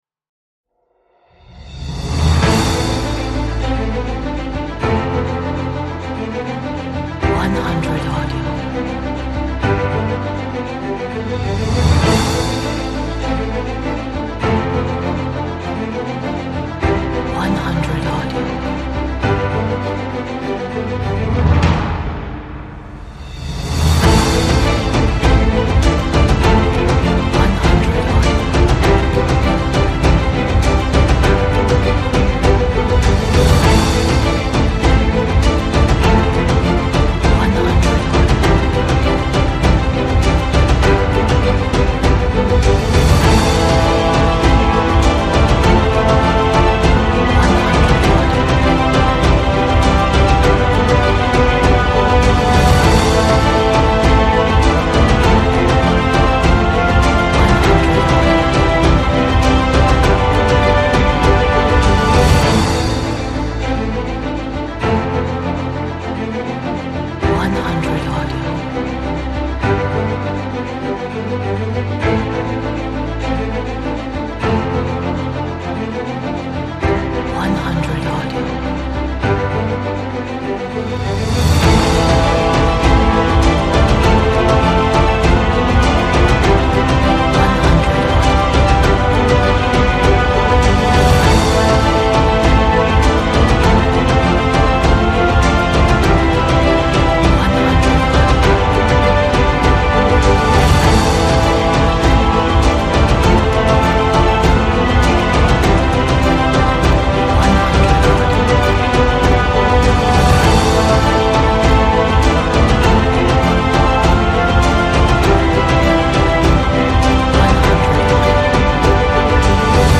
Inspiring Cinematic epic music.